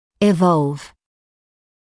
[ɪ’vɔlv][и’волв]эволюционировать, развиваться